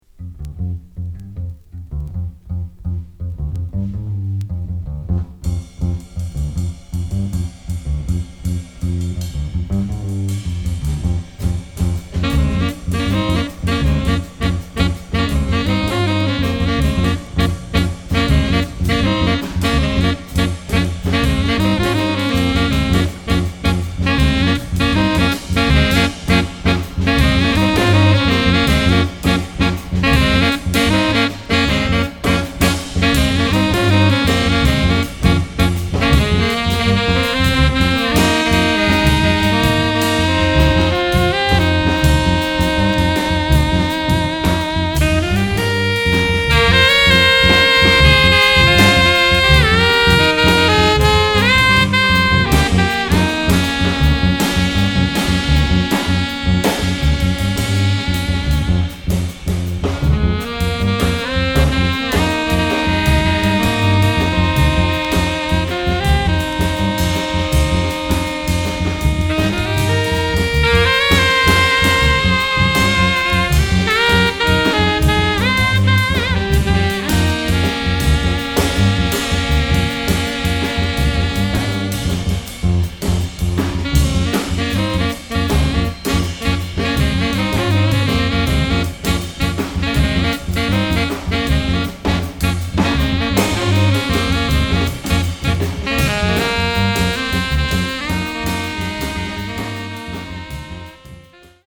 即興